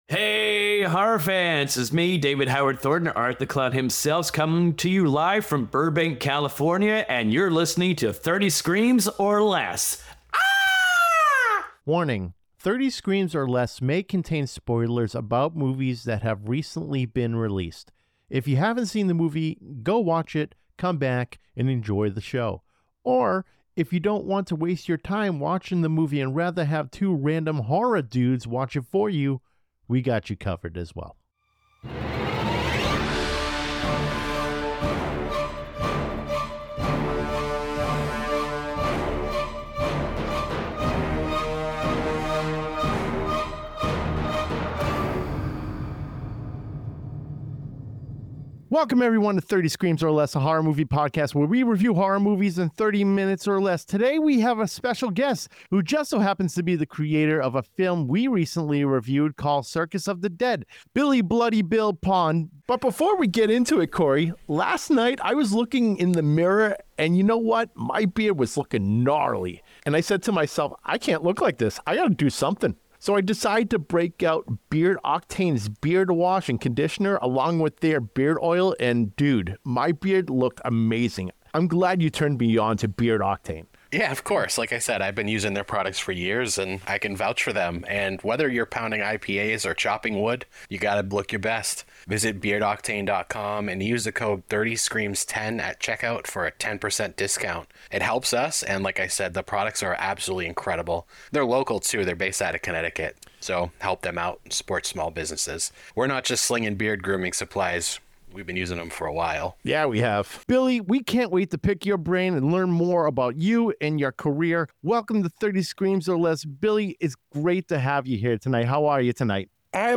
In this episode of the 30 Screams or Less podcast we interview